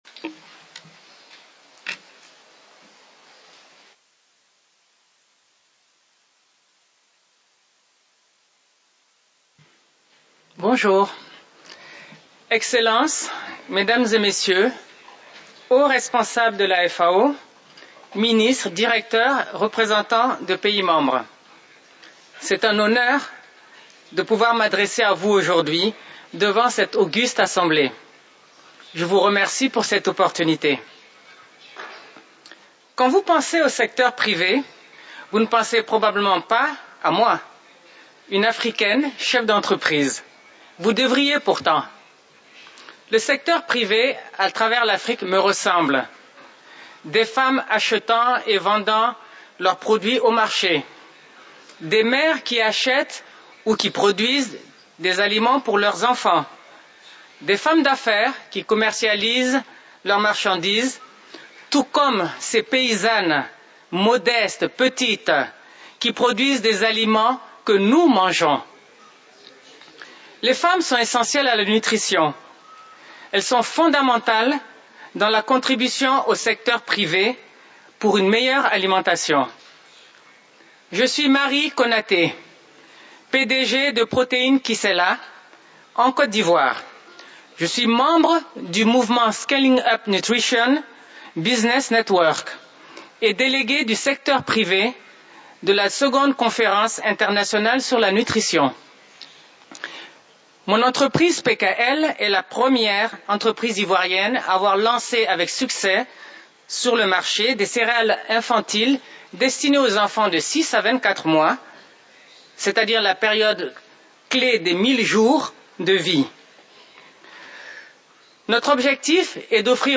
Second International Conference on Nutrition (ICN2), 19-21 November 2014
Outcomes of the Private Sector Event